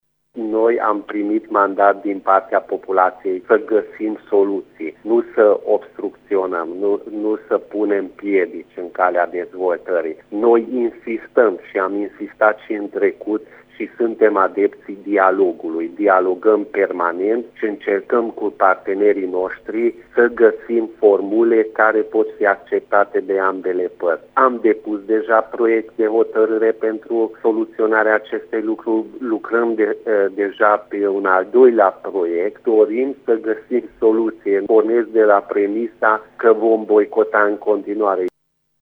În privinţa unui viitor boicot al UDMR la şedinţa ordinară programată la finele lunii, Peti Andras a arătat că se caută soluţii pentru rezolvarea situaţiei ivite: